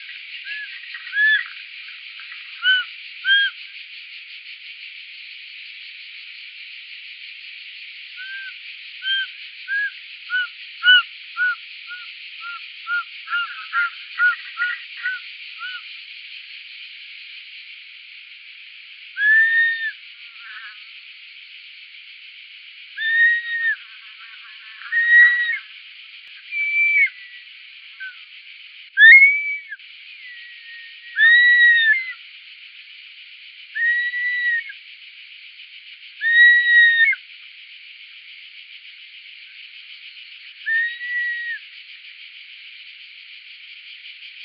E 11° 16' - ALTITUDE: +130 m. - VOCALIZATION TYPE: flight and threat(?)calls.
Background: Cicada stridulations, flying insect noise, human voice.